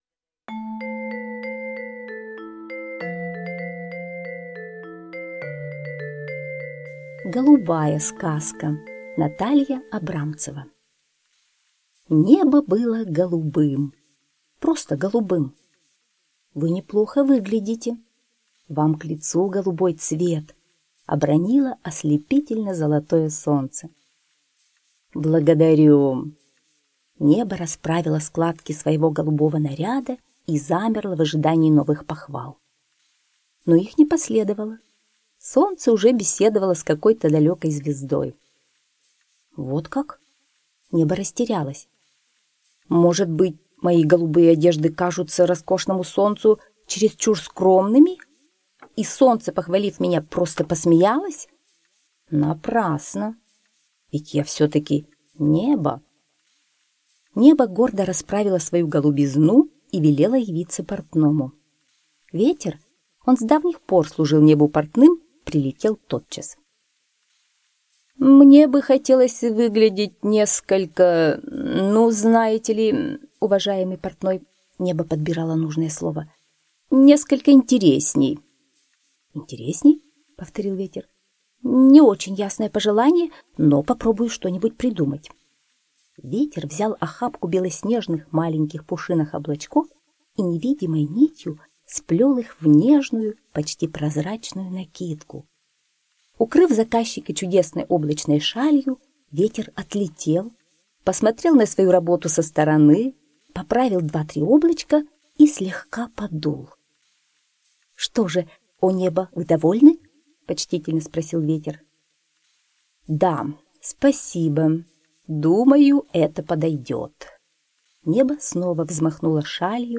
Слушайте Голубая сказка - аудиосказка Абрамцевой Н. Сказка про небо, которому хотелось выглядеть красиво, чтобы солнце похвалило его еще раз.